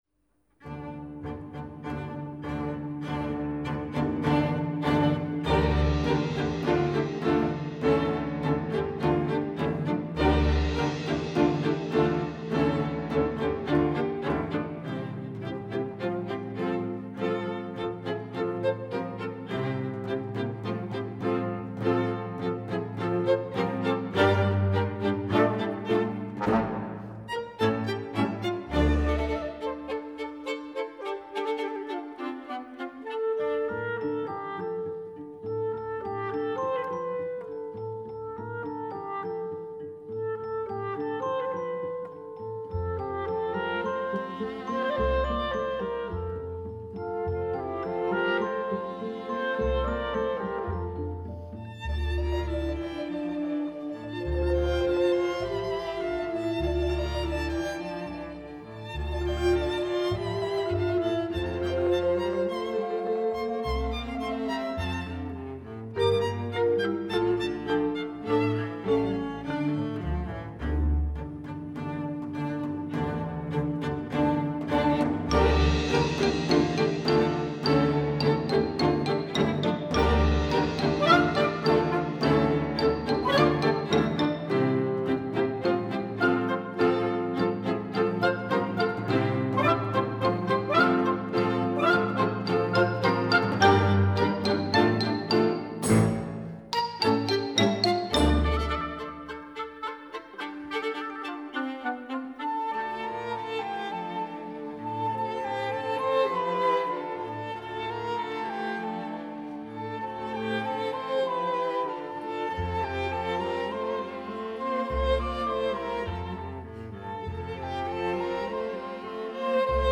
Epoque :  Musique d'aujourd'hui
Genre :  Opéra / Opérette
Effectif :  UnissonVoix égales